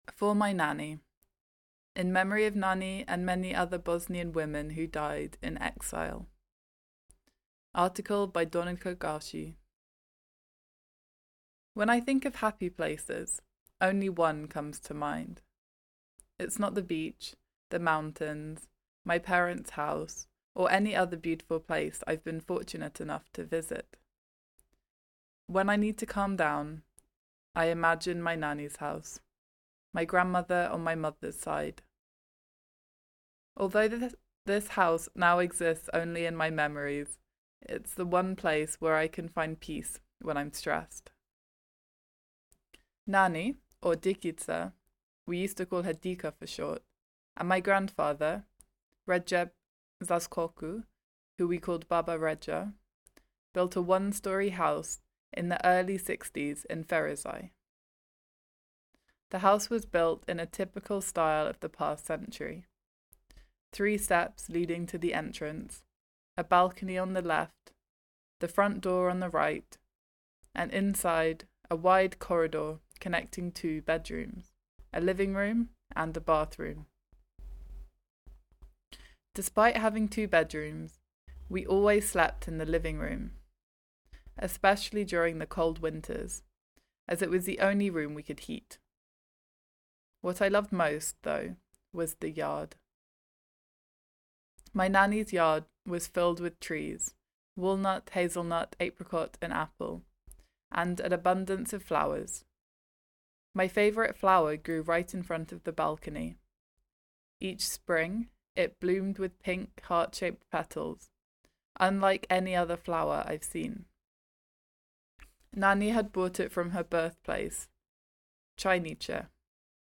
Below is a read-aloud version of the entire article.